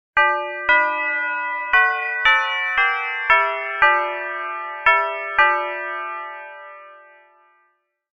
Doorbell Midnight Clear Christmas Melody Sound Effect
Festive doorbell sound effect with a clear Christmas melody, perfect for holiday scenes and winter videos.
Genres: Sound Effects
Doorbell-midnight-clear-christmas-melody-sound-effect.mp3